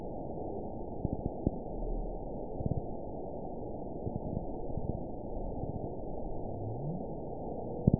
event 921707 date 12/16/24 time 23:40:27 GMT (10 months, 1 week ago) score 8.60 location TSS-AB04 detected by nrw target species NRW annotations +NRW Spectrogram: Frequency (kHz) vs. Time (s) audio not available .wav